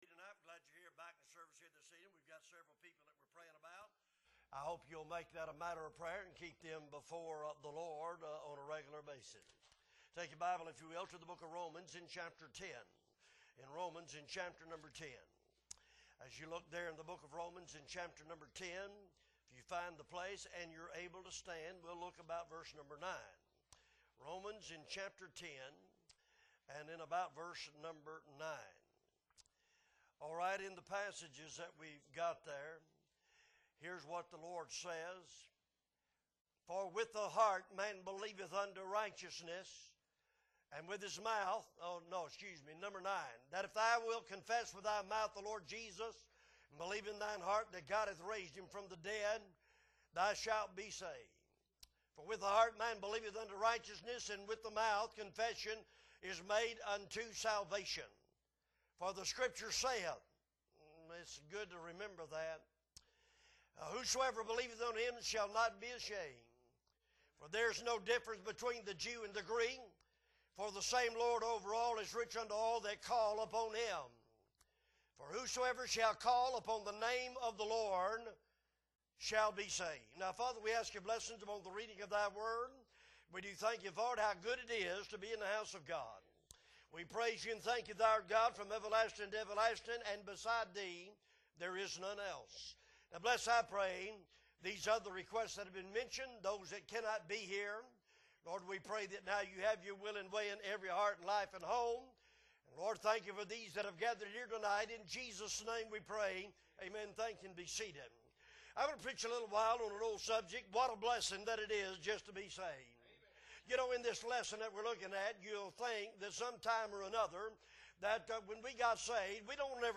October 1, 2023 Sunday Evening Service - Appleby Baptist Church